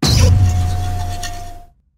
screamtail_ambient.ogg